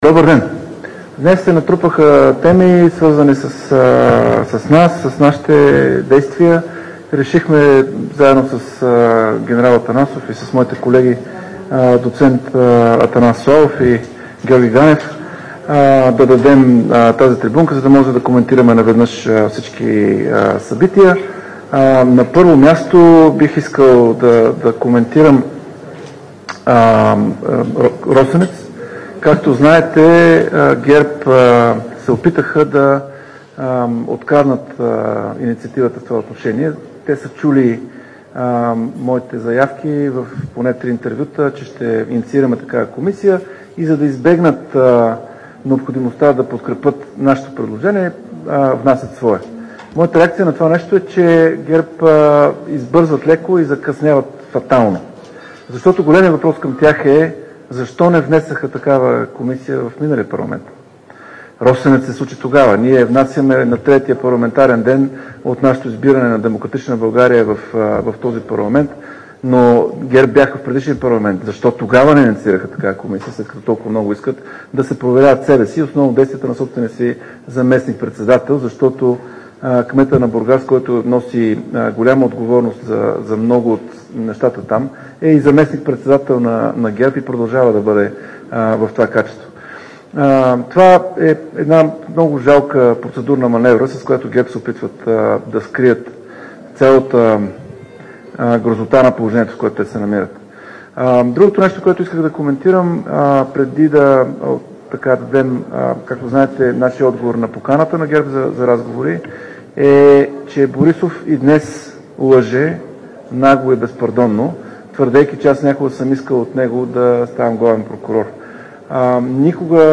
11.40 - Брифинг на ПГ на „Изправи се! Мутри вън!". - директно от мястото на събитието (Народното събрание)
Директно от мястото на събитието